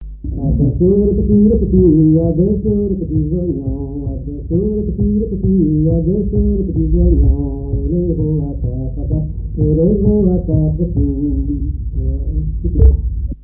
branle : courante, maraîchine
Répertoire à l'accordéon diatonique
Pièce musicale inédite